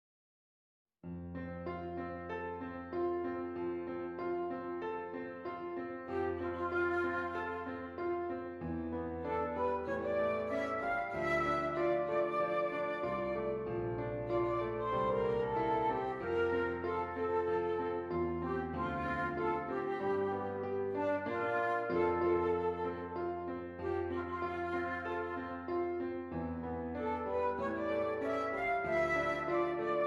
Flute Solo with Piano Accompaniment
F Major
Moderately